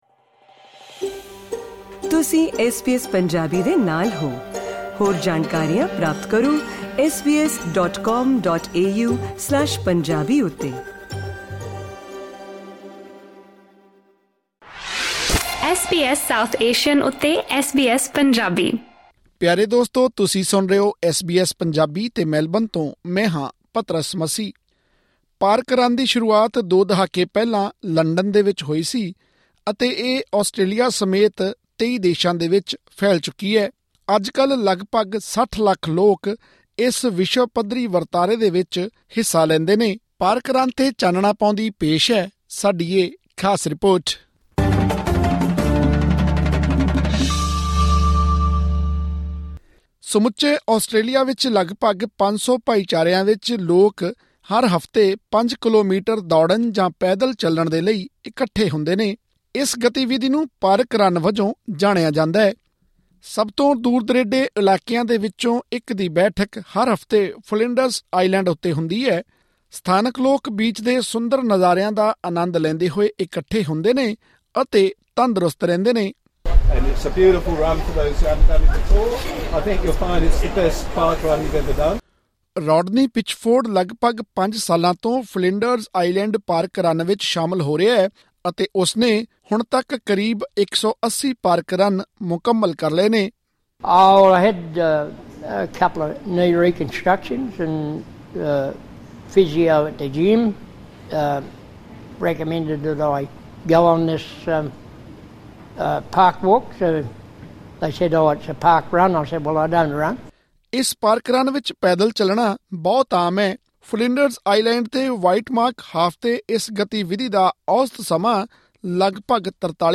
ਪਾਰਕ ਰਨ ਨਾਲ ਜੁੜਨ ਅਤੇ ਹੋਰ ਵੇਰਵੇ ਹਾਸਲ ਕਰਨ ਲਈ ਸੁਣੋ ਇਹ ਰਿਪੋਰਟ.....